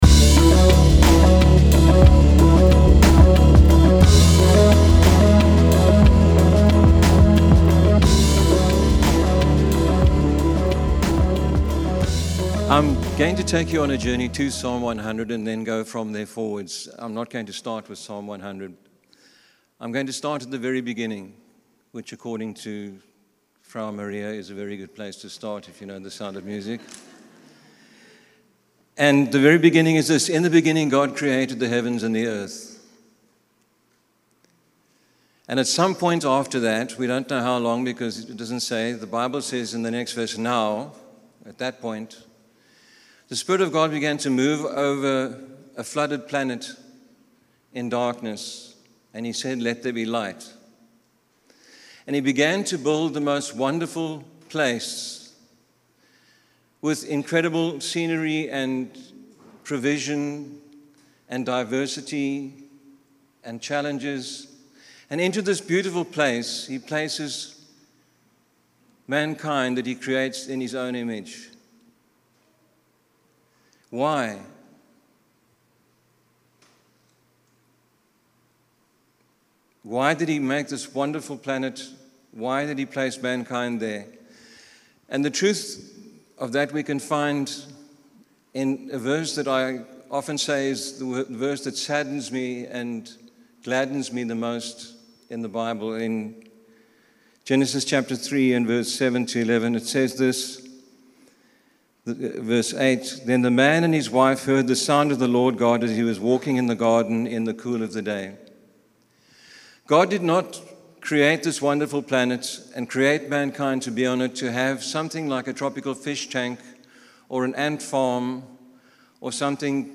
Here you have a chance to listen to the sermons at Forest Town Church. We are a local church based in St Albans and we trust that you will find these messages helpful and a true blessing to your life.